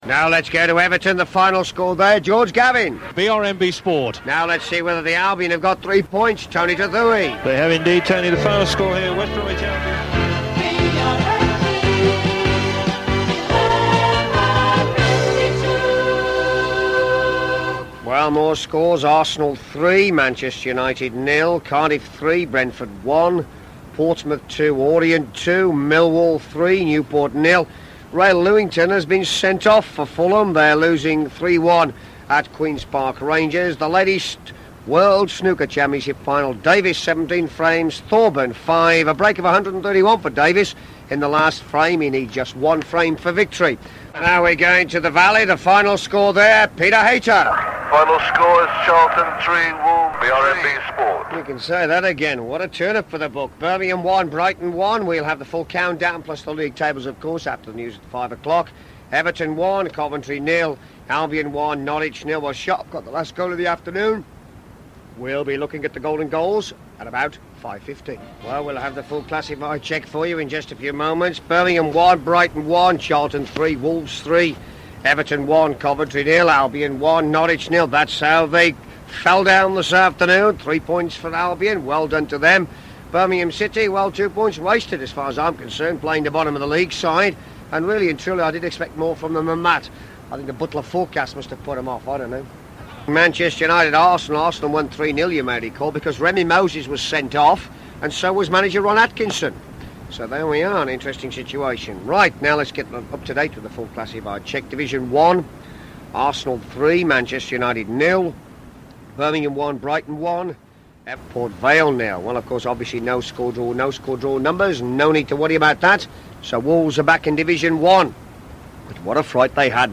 Forthright and direct. Measured theatrical anger, genuine yet never nasty.
Banter.